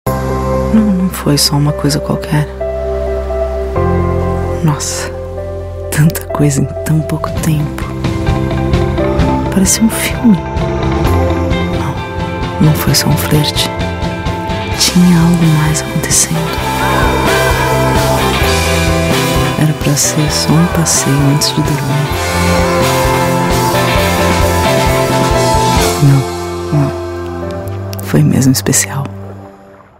Feminino
Voz Madura 00:30